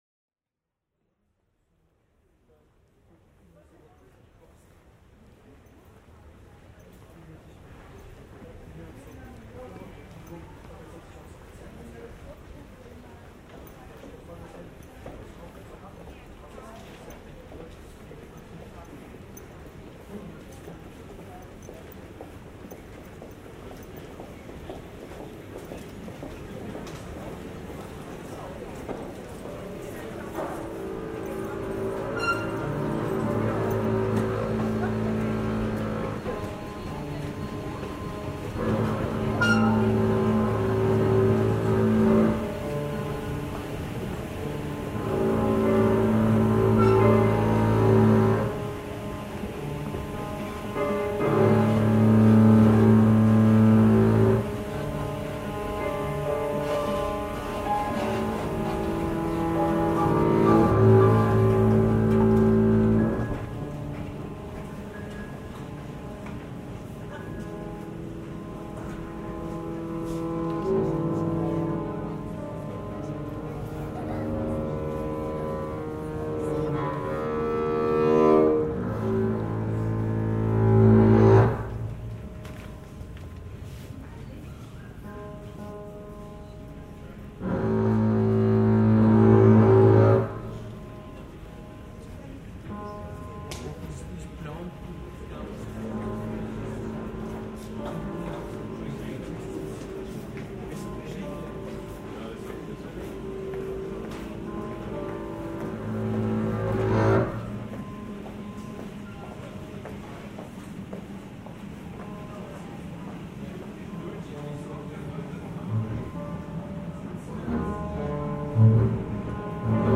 In an interplay of confused everyday noises and musical improvisation on the double bass and piano, a carpet of sound is created in reflection of the visual depiction.
In einem Wechselspiel von verwirrenden Alltagsgeräuschen und musikalischer Improvisation auf Kontrabass und Klavier entsteht ein Klangteppich, der die visuelle Darstellung reflektiert.